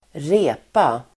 Uttal: [²r'e:pa]